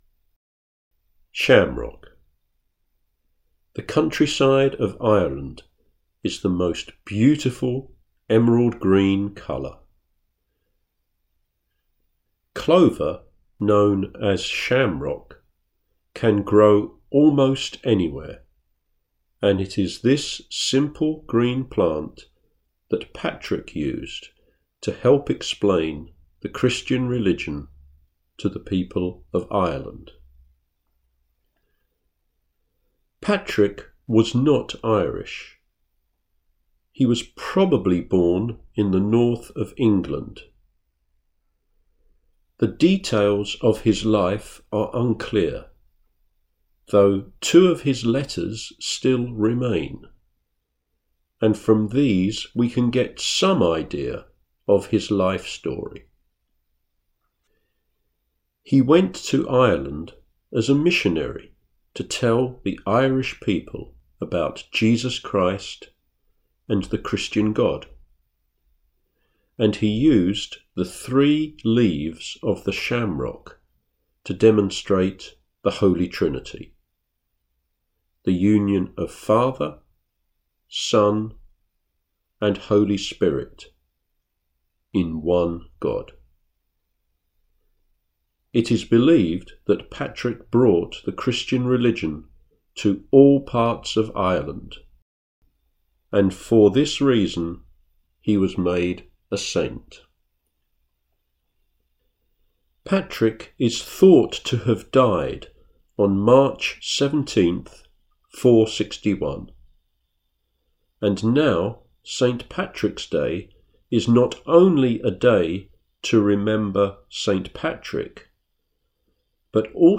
Shamrock, audio narration